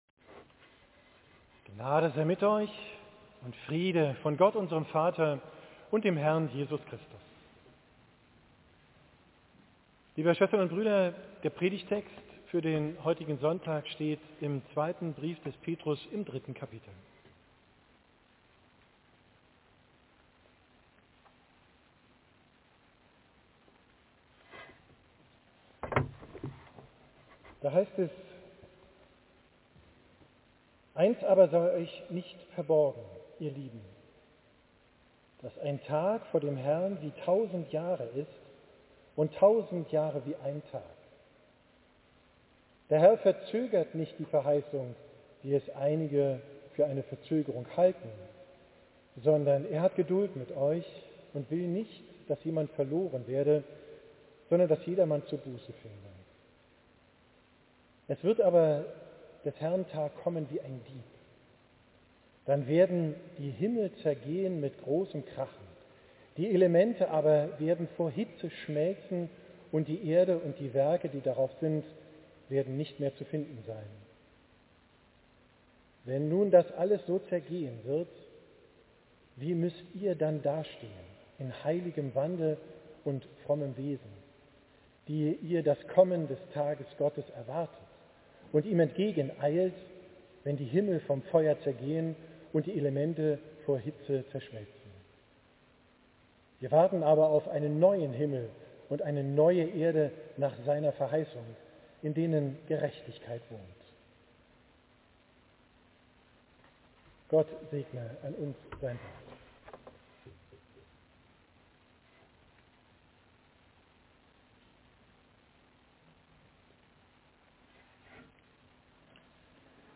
Predigt vom Ewigkeitssonntag/Totensonntag, 26.